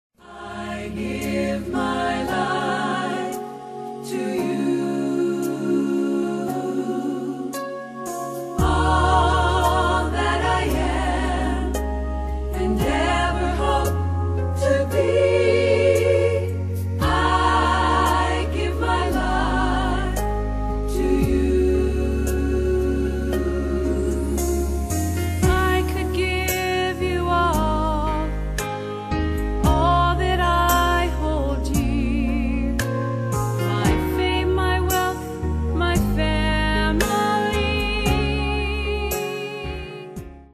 with her velvet voice, sings the solo on this song.